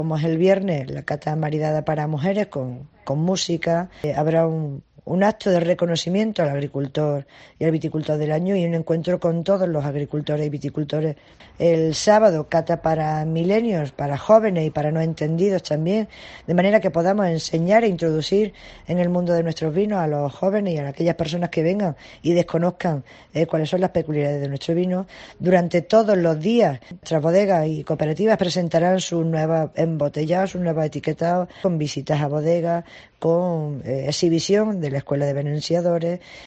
Escucha a Paqui Carmona, alcaldesa de Moriles sobre la Cata del Vino 2018